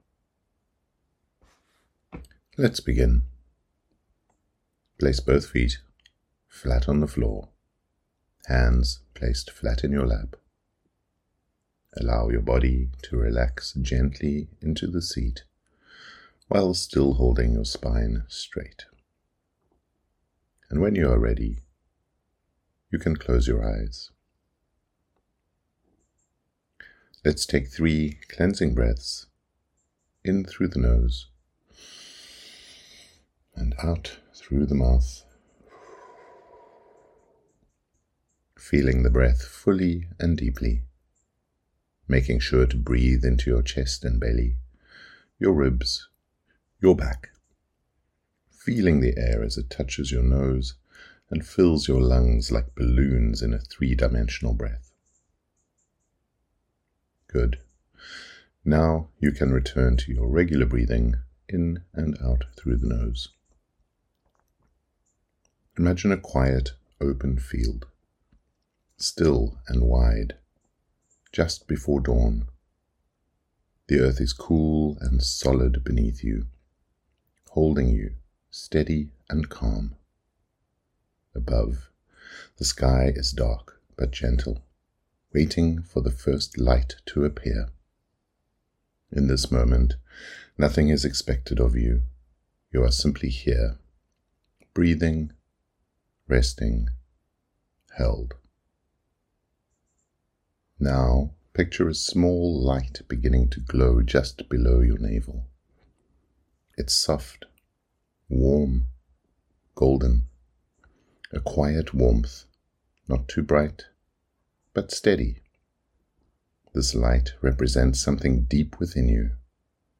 Golden Circle Meditation
MT01-meditation-golden-circle.mp3